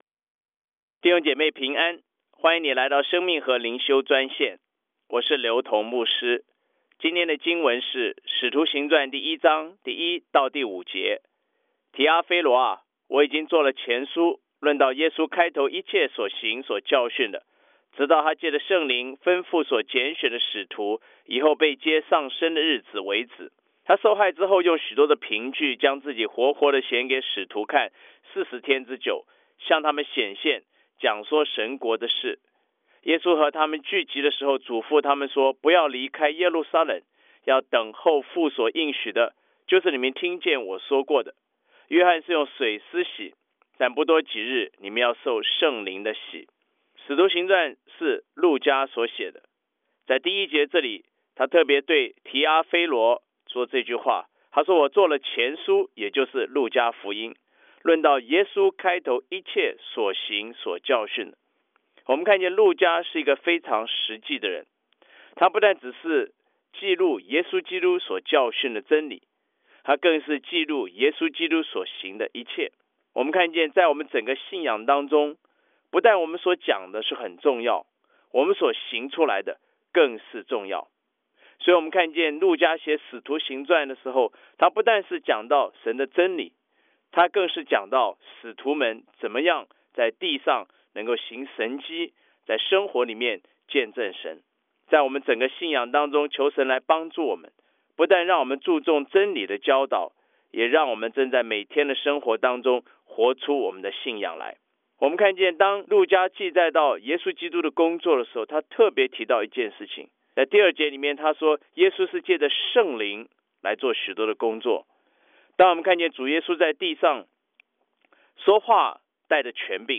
藉着每天五分钟电话分享，以生活化的口吻带领信徒逐章逐节读经